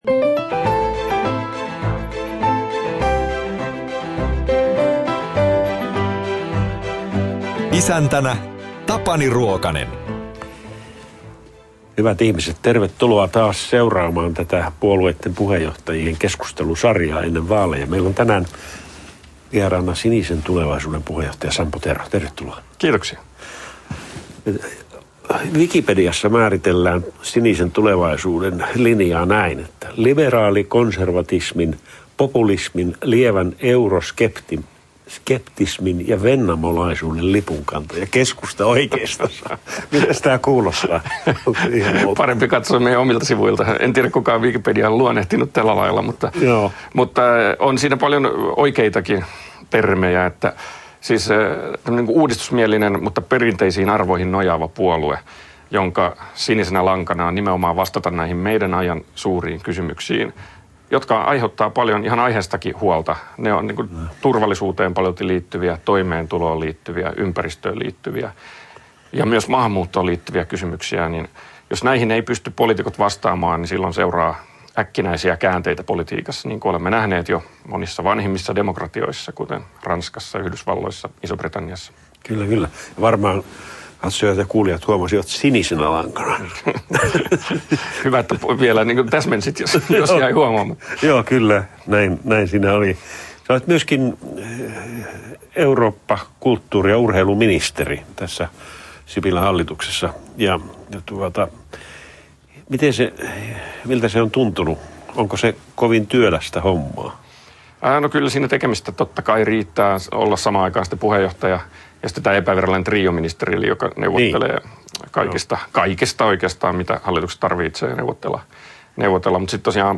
Kääntääkö yhden prosentin ministeri puolueensa suunnan? – puheenjohtajatentissä Sampo Terho